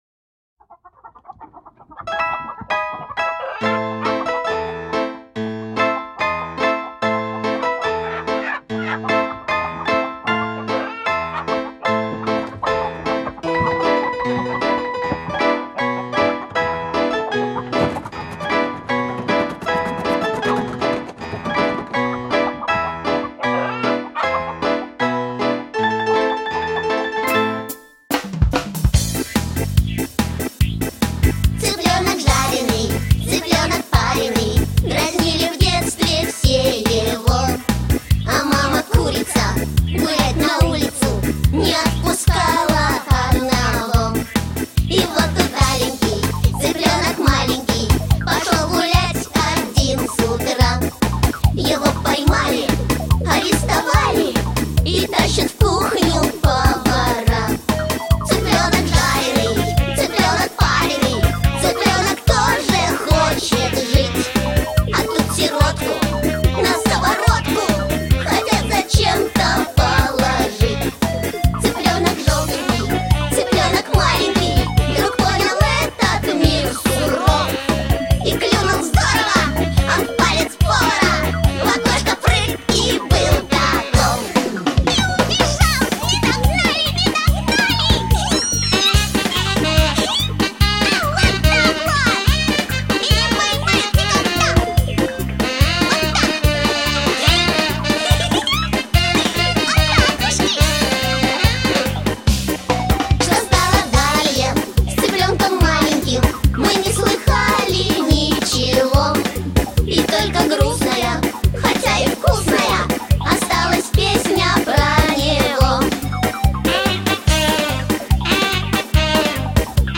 • Качество: Хорошее
• Категория: Детские песни
шуточная